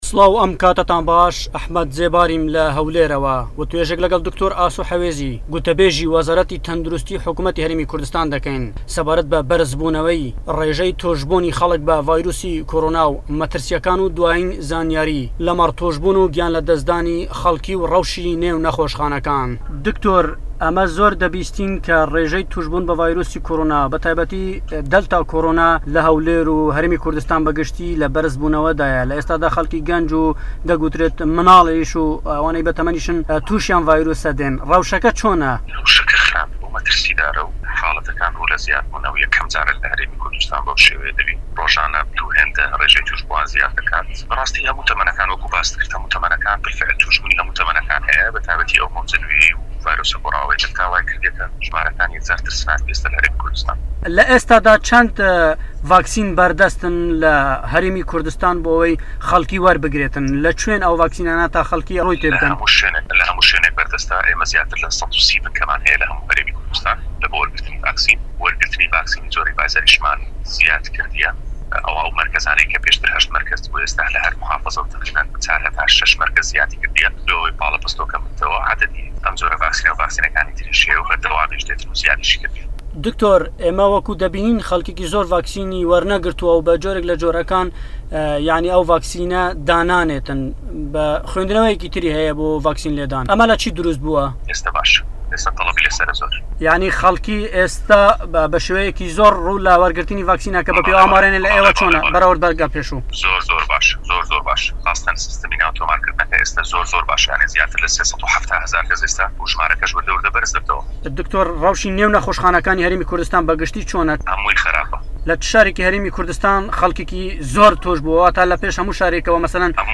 لەم وتووێژەدا لەگەڵ دەنگی ئەمەریکا